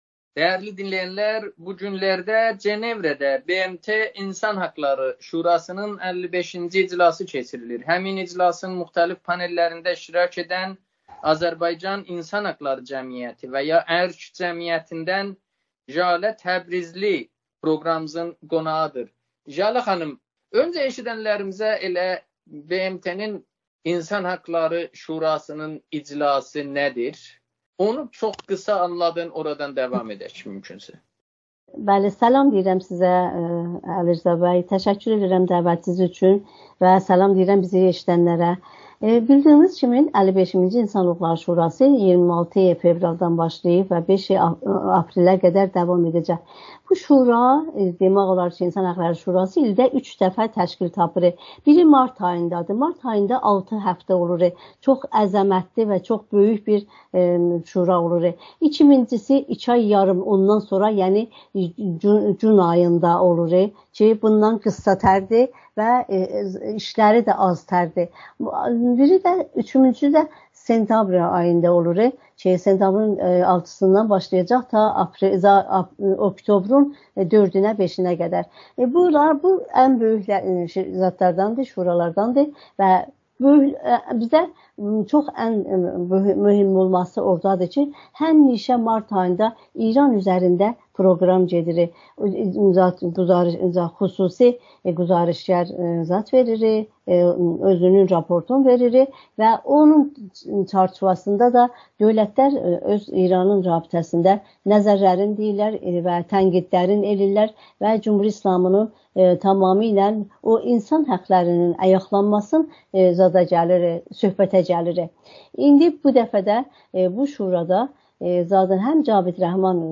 Amerikanın Səsinə müsahibədə